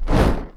FireBall.wav